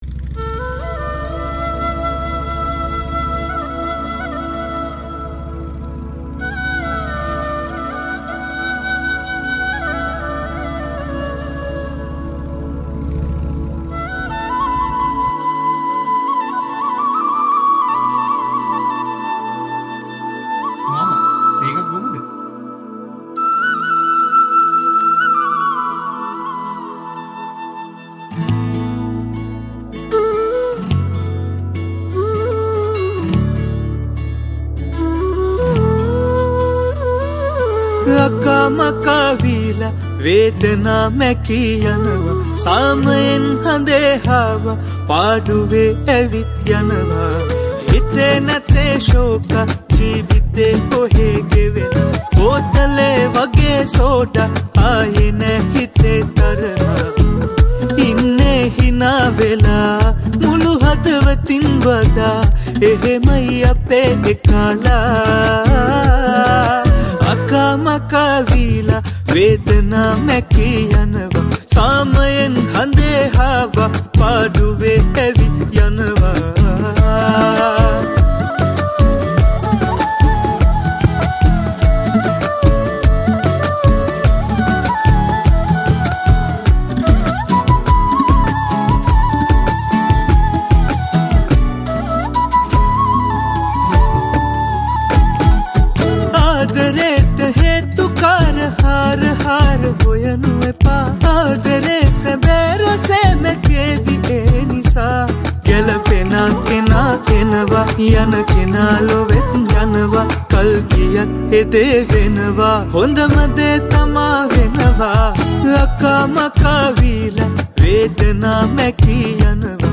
Guitars
Percussion
Flute